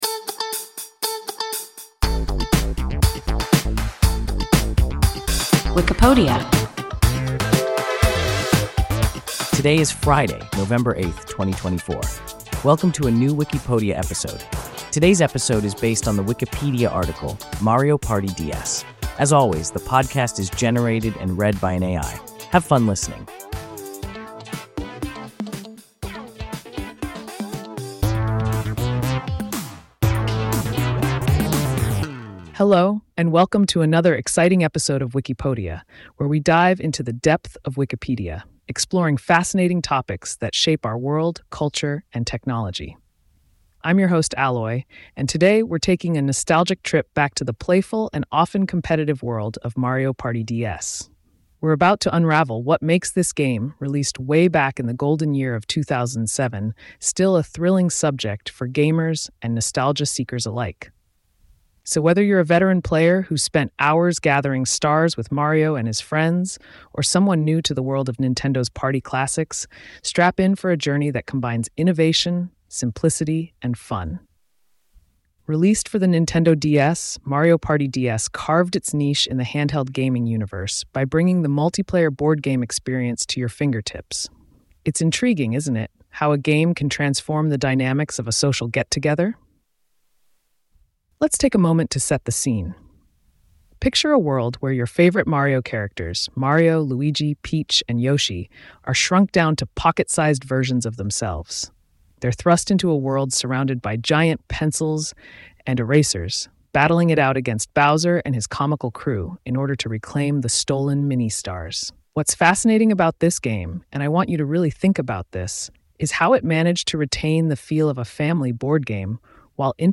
Mario Party DS – WIKIPODIA – ein KI Podcast